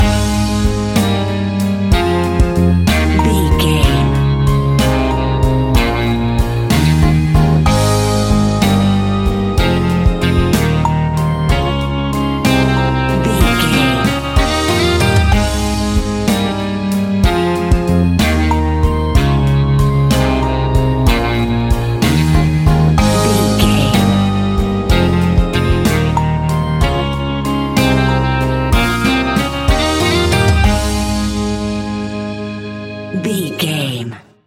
Uplifting
Ionian/Major
A♭
pop rock
indie pop
fun
energetic
cheesy
acoustic guitars
drums
bass guitar
electric guitar
piano
electric piano
organ